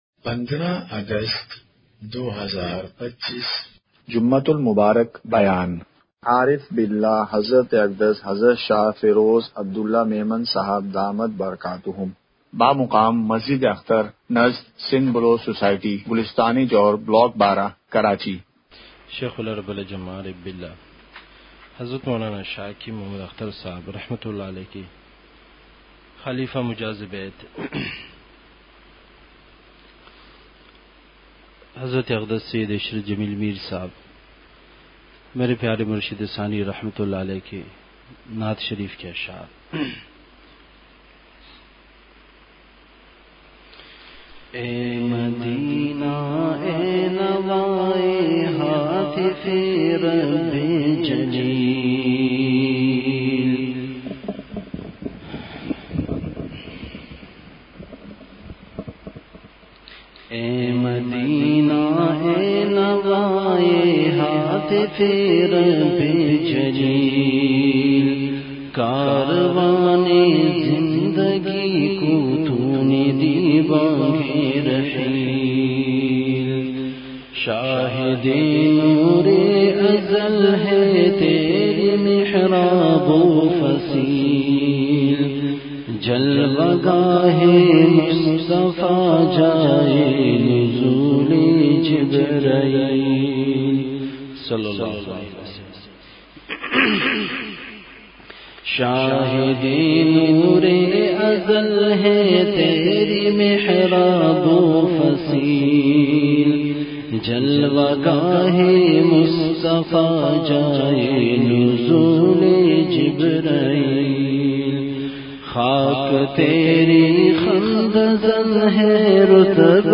جمعہ بیان ۱۵ / اگست ۲۵ء:سنت کے طریقے میں کامیابی ہے !
مقام:مسجد اختر نزد سندھ بلوچ سوسائٹی گلستانِ جوہر کراچی